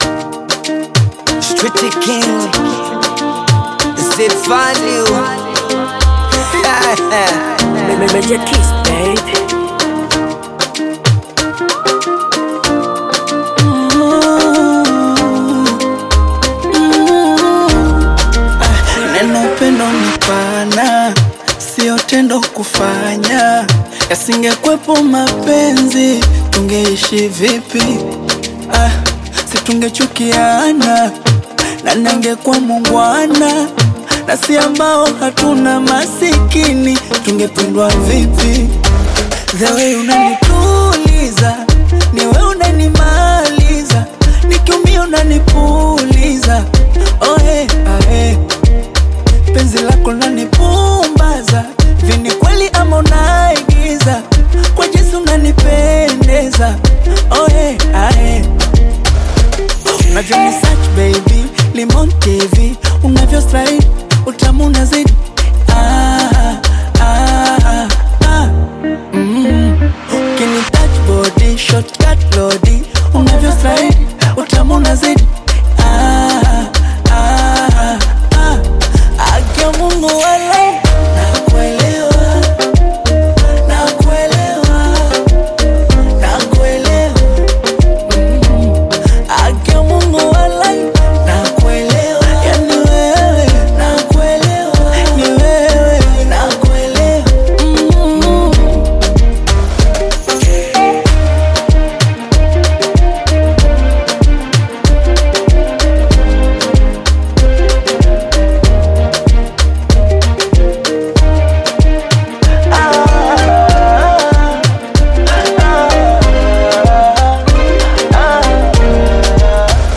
R&B
Love song